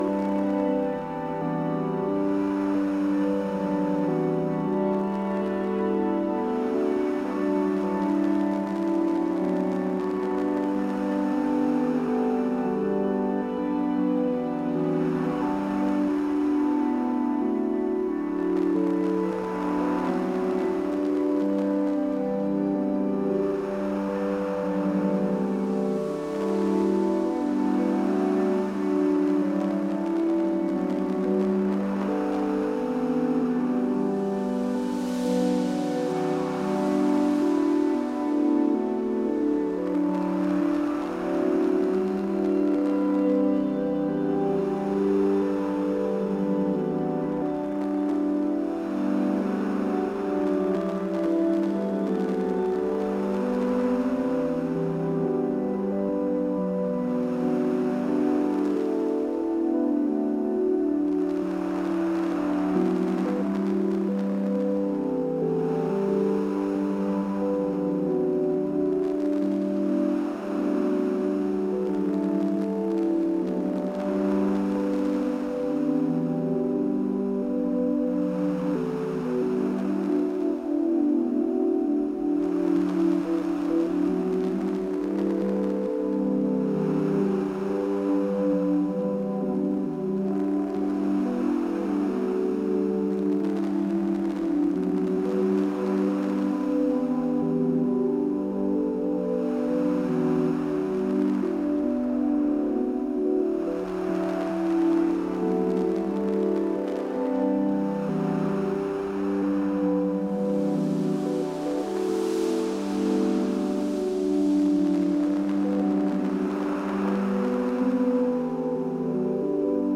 Landing Modular Synth December 3, 2024 Download (audio/mpeg) ambient test modular sketch Hello, This is a test of the audio playing ADVANCED messaging system.